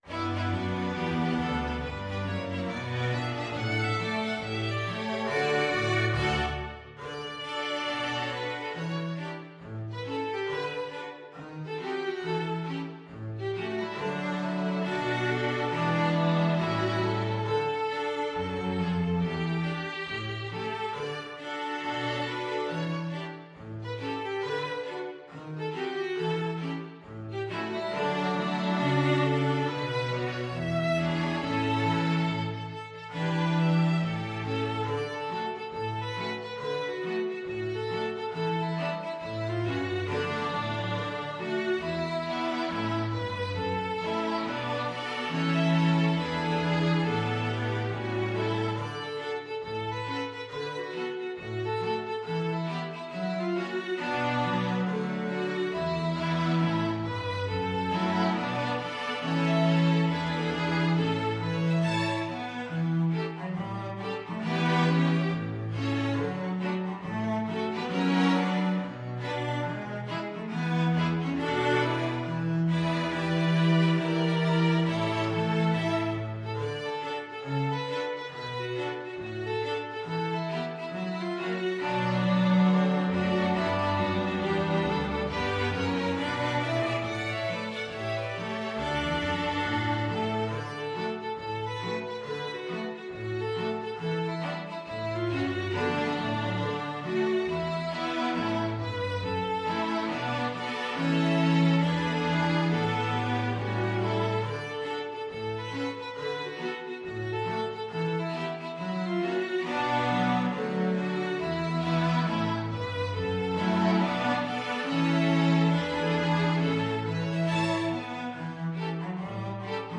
Violin 1Violin 2ViolaCelloDouble Bass
4/4 (View more 4/4 Music)
With a swing =c.69
Pop (View more Pop String Ensemble Music)